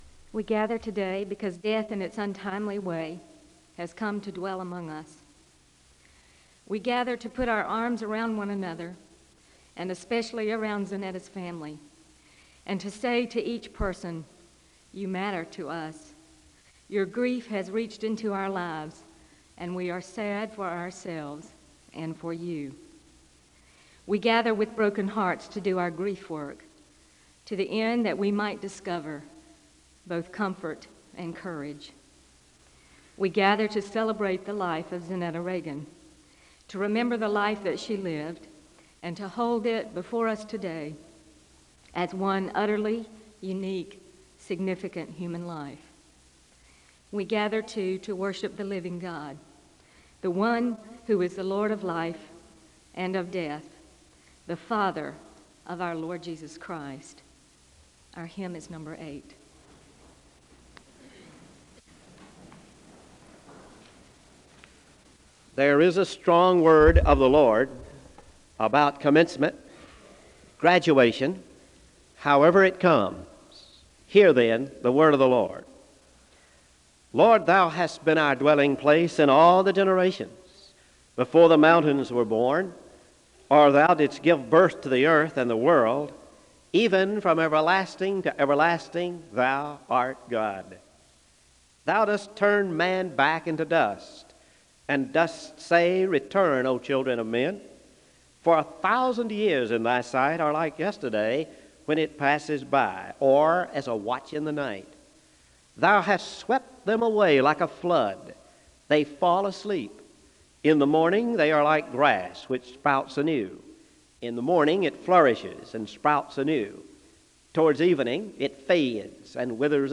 The service ends with a benediction (22:17-22:57).
Memorial service